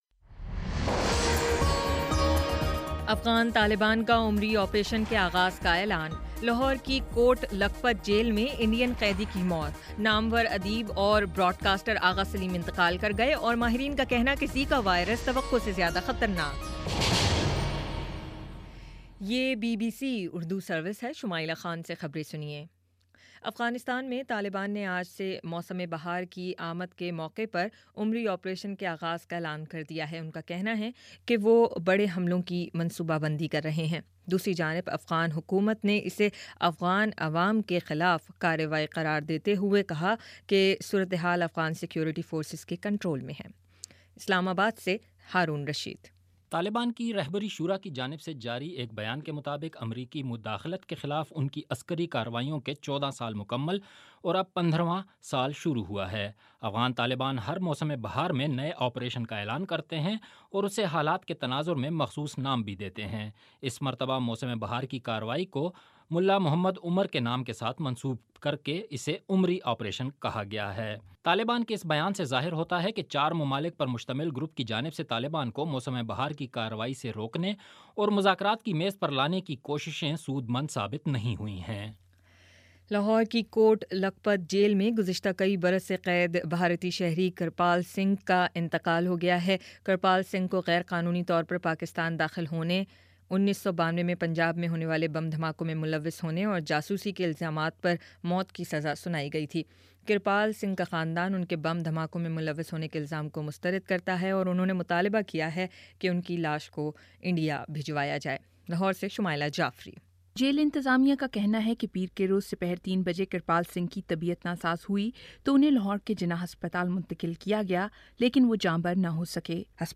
اپریل 12 : شام پانچ بجے کا نیوز بُلیٹن